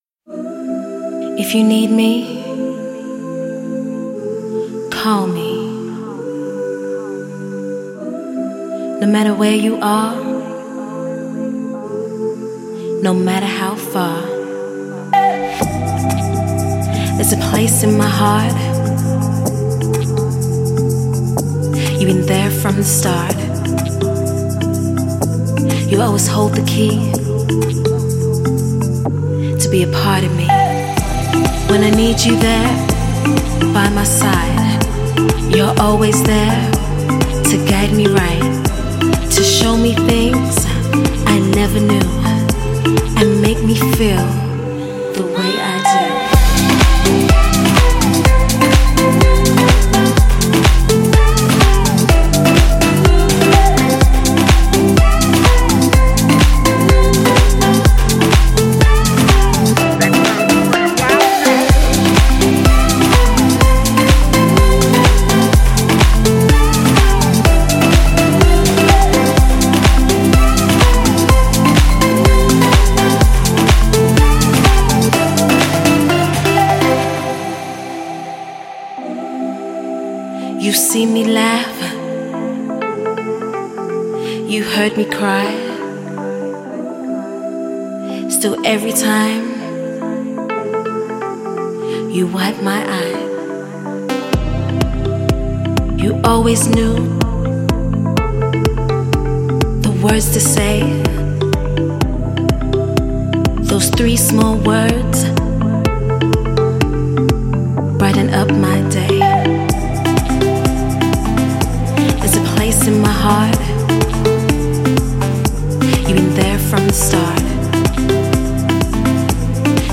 Žánr: Electro/Dance
The perfect chill song